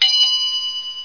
DING1.mp3